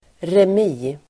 Ladda ner uttalet
remi substantiv, draw Uttal: [rem'i:] Böjningar: remien, remier Synonymer: oavgjort Definition: oavgjort slutresultat i schack (undecided result in chess) draw substantiv, oavgjord match , remi [schack]